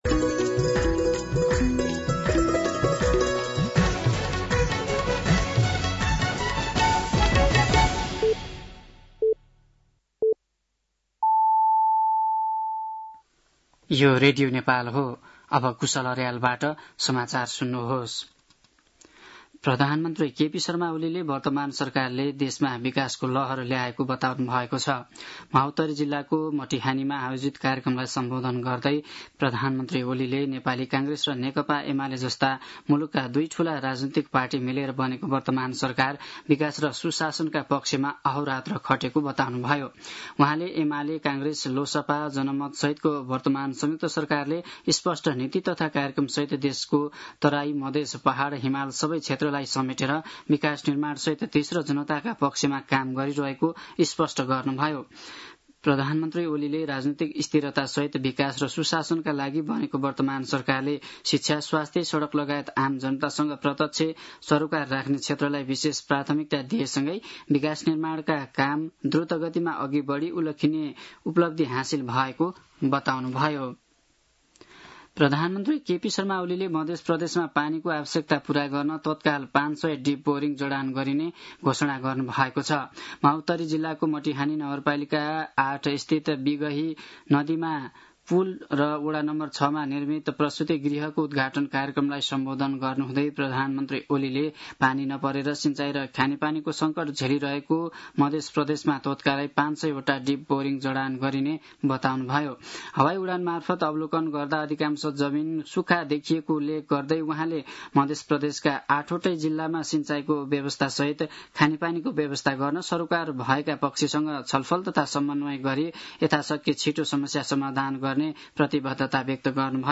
साँझ ५ बजेको नेपाली समाचार : ९ साउन , २०८२
5-pm-nepali-news-4-09.mp3